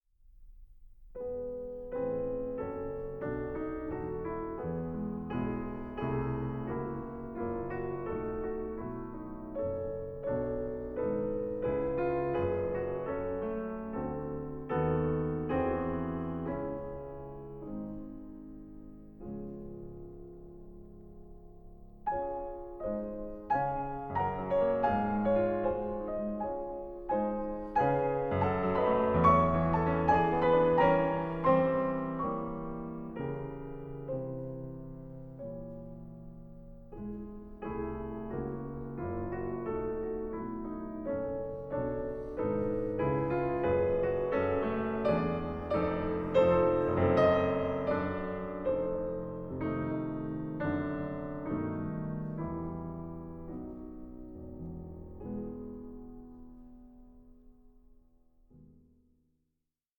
piano
By turns placid, sparse, restive and impassioned